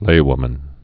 (lāwmən)